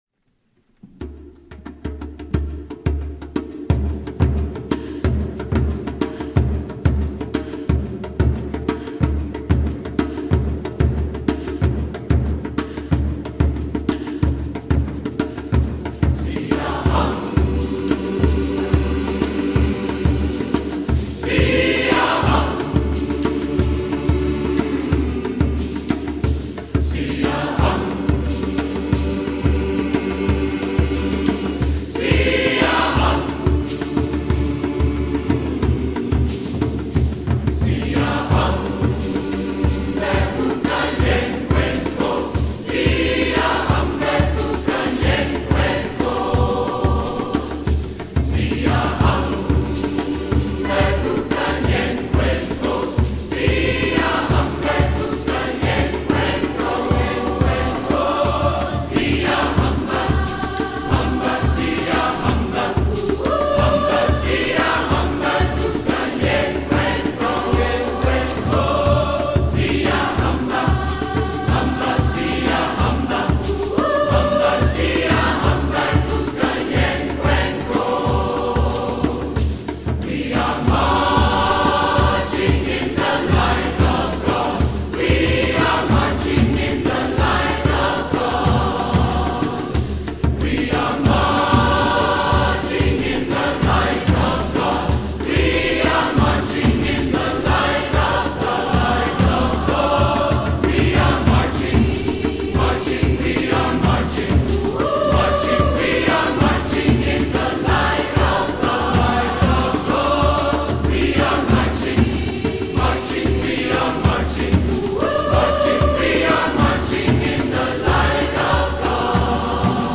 Chœur d’hommes fondé en 1860
Chant traditionnel zoulou
par Angel City Chorale
H10477-Live.mp3